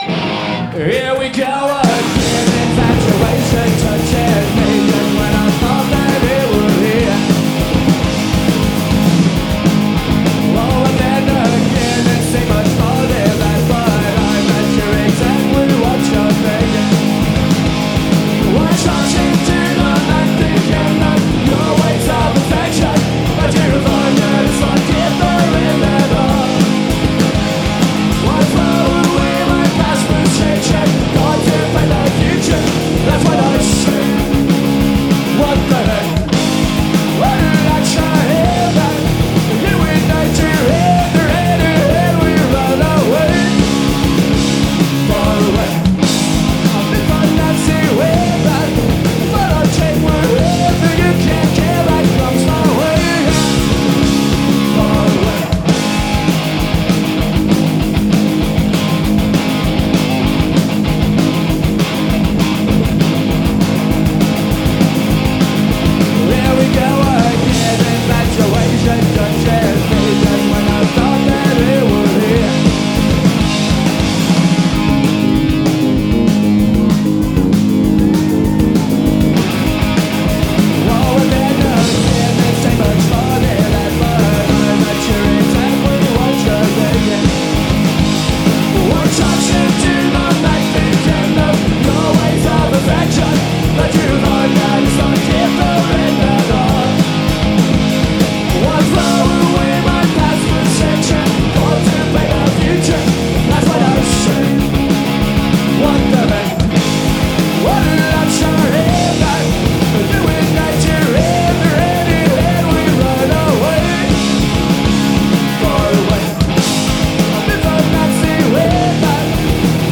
Live at Garatge Club, Barcelona 1994